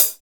POP CHH 2.wav